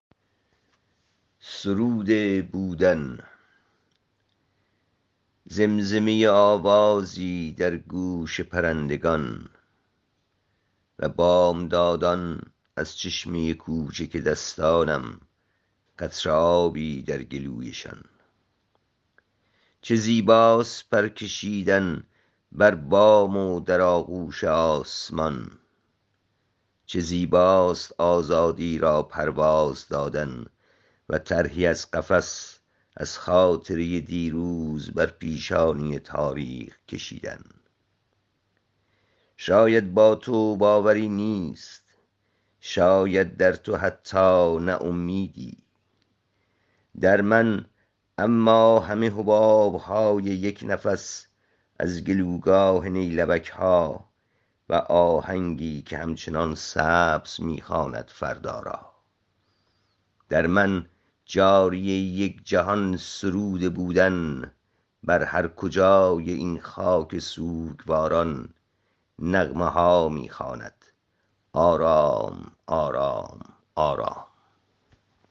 این شعر را با صدای شاعر بشنوید